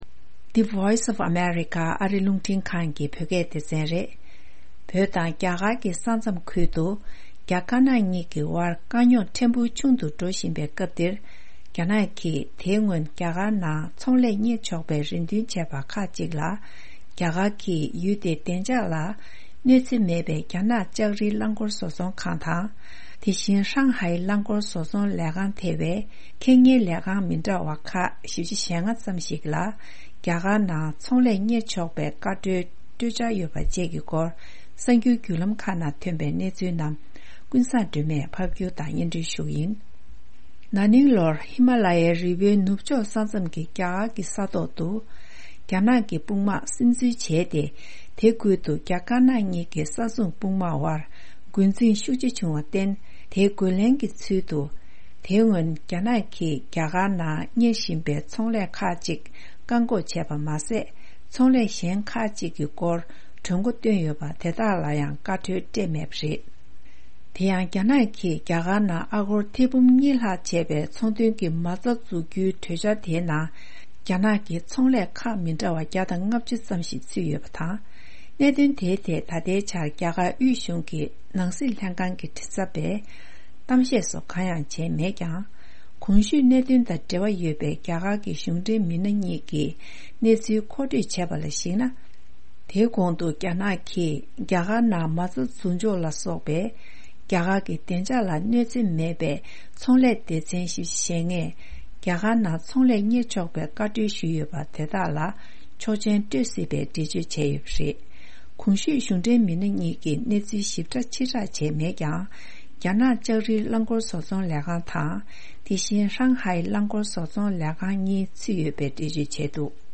ཕབ་བསྒྱུར་དང་སྙན་སྒྲོན་ཞུ་ཡི་རེད།